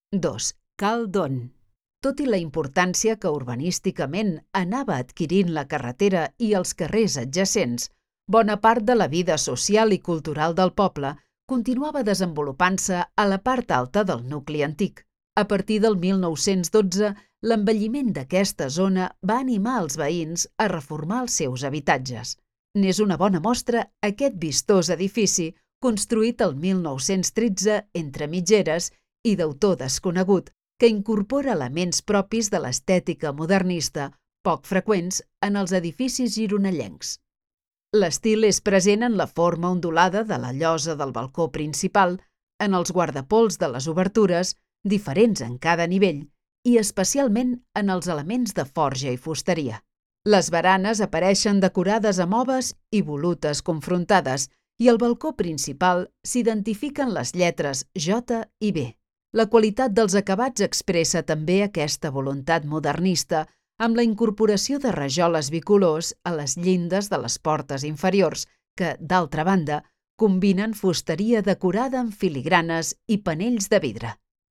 Audioguia